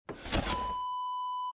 Dresser Drawer Close Wav Sound Effect #2
Description: The sound of a wooden dresser drawer being closed
Properties: 48.000 kHz 16-bit Stereo
A beep sound is embedded in the audio preview file but it is not present in the high resolution downloadable wav file.
Keywords: wooden, dresser, drawer, push, pushing, close, closing
drawer-dresser-close-preview-2.mp3